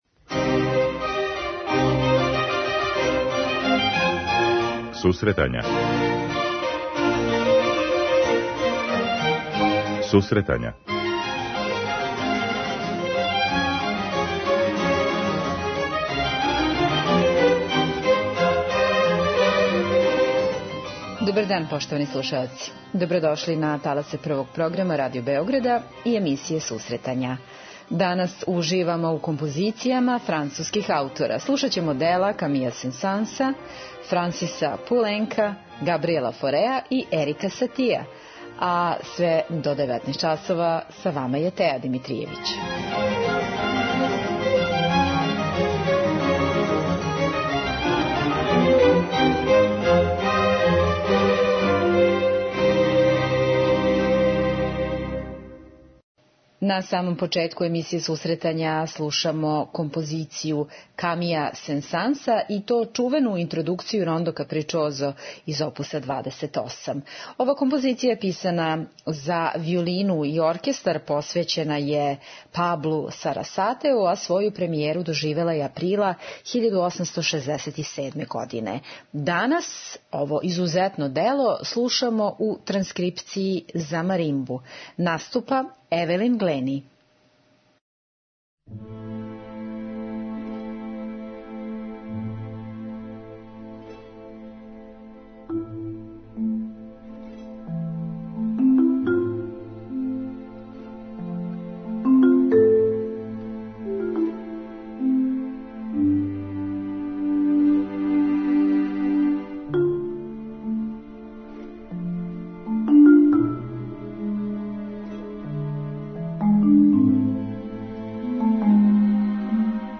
преузми : 10.21 MB Сусретања Autor: Музичка редакција Емисија за оне који воле уметничку музику.